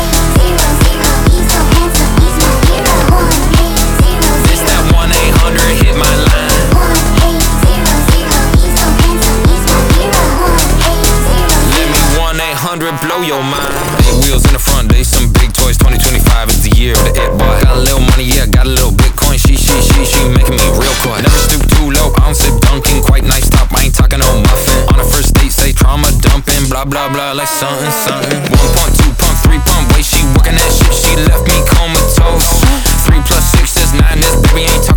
Pop Dance
Жанр: Поп музыка / Танцевальные